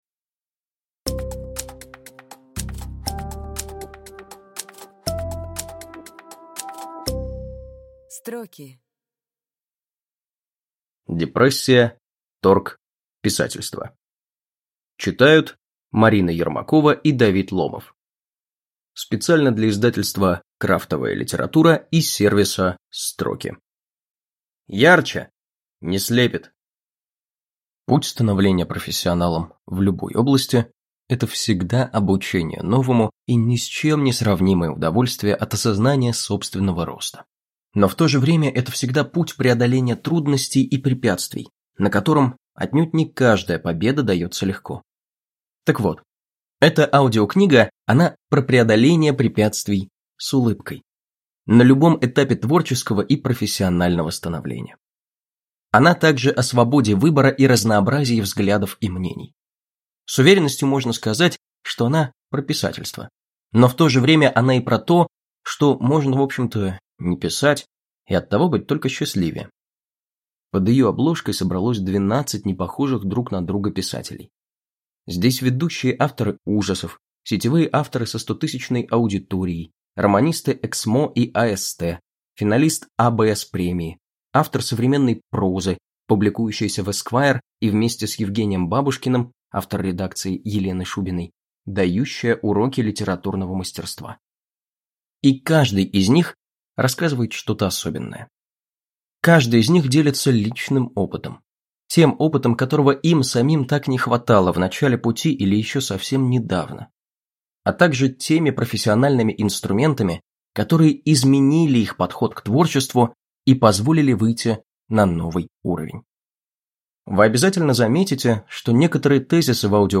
Аудиокнига Депрессия. Торг. Писательство | Библиотека аудиокниг